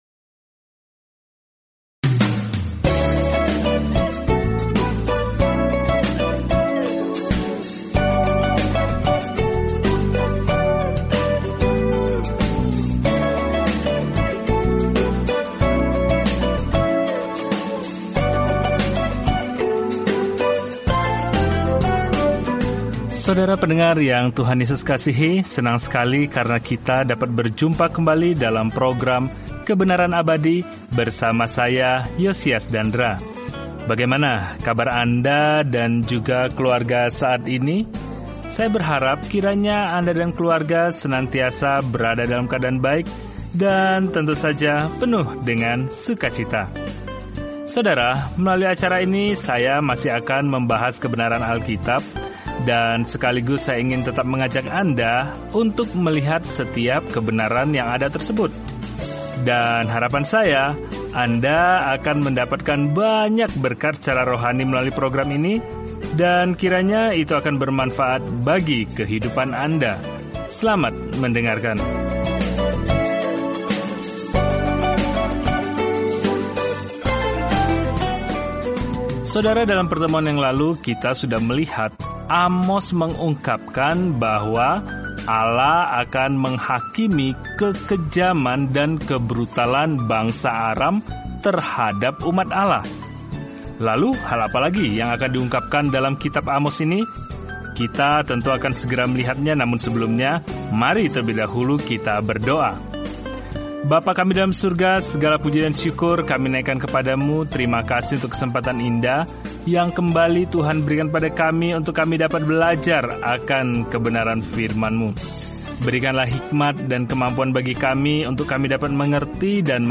Firman Tuhan, Alkitab Amos 1:4-15 Hari 2 Mulai Rencana ini Hari 4 Tentang Rencana ini Amos, seorang pengkhotbah di pedesaan, pergi ke kota besar dan mengutuk cara-cara mereka yang penuh dosa, dengan mengatakan bahwa kita semua bertanggung jawab kepada Tuhan sesuai dengan terang yang Dia berikan kepada kita. Jelajahi Amos setiap hari sambil mendengarkan studi audio dan membaca ayat-ayat tertentu dari firman Tuhan.